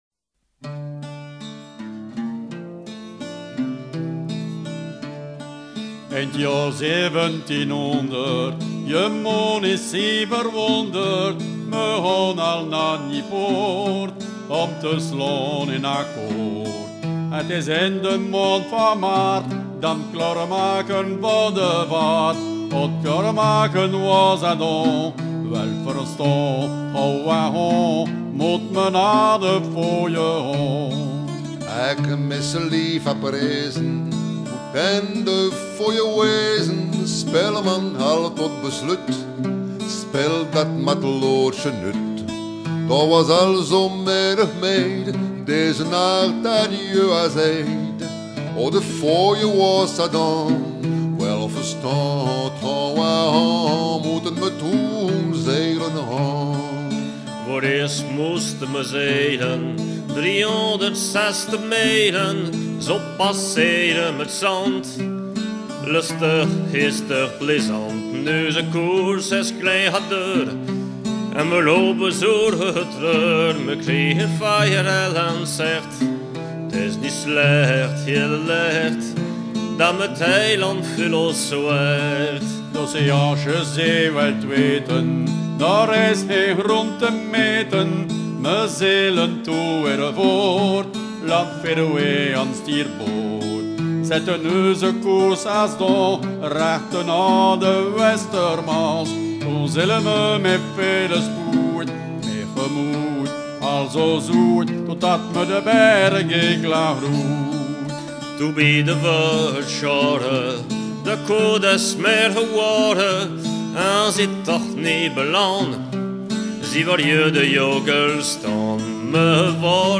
circonstance : maritimes
Genre strophique